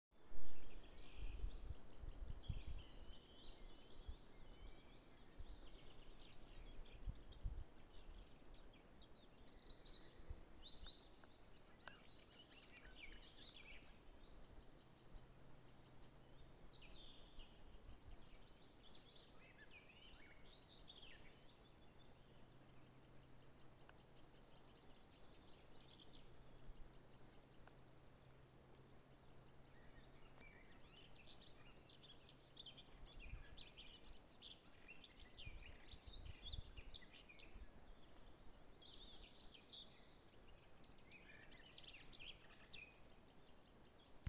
садовая славка, Sylvia borin
Administratīvā teritorijaLimbažu novads
СтатусПоёт